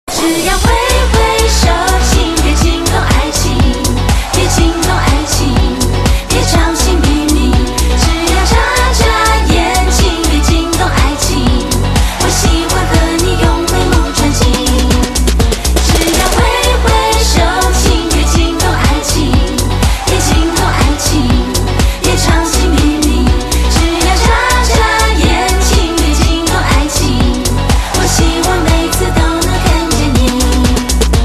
M4R铃声, MP3铃声, 华语歌曲 45 首发日期：2018-05-15 00:47 星期二